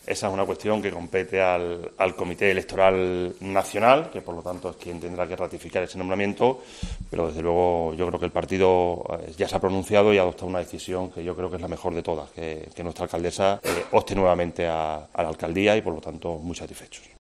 Rafael Mateos. portavoz Gobierno local en Cáceres